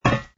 fs_ml_steel03.wav